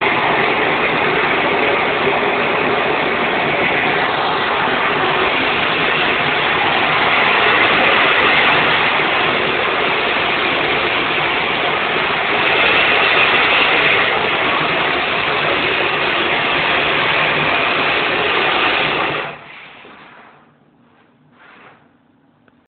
Tornato in ufficio mi stavo arrovellando su quale suoneria adottare, quando all’improvviso al piano di sopra hanno iniziato a raschiare il pavimento per poi mettere il parquet.
Ho dunque approfittato della imperdibile occasione ed ho registrato 22 secondi di questo che adesso è diventata la mia nuova suoneria.